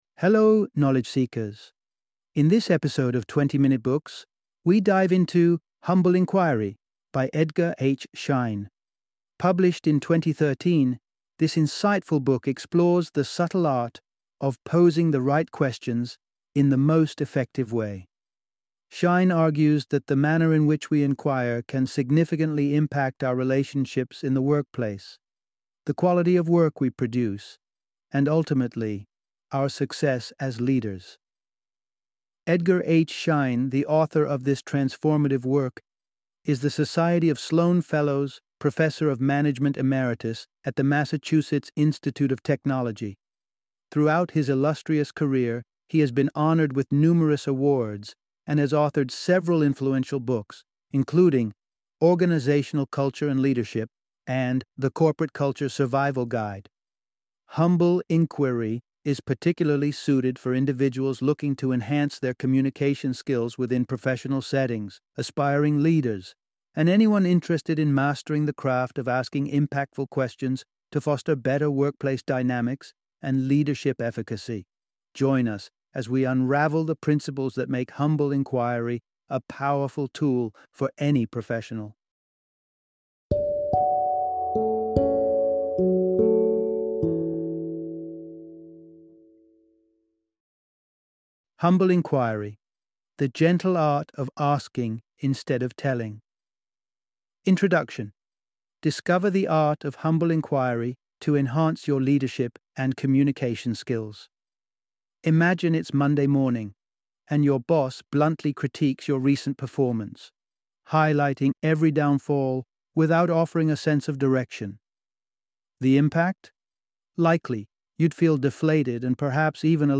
Humble Inquiry - Audiobook Summary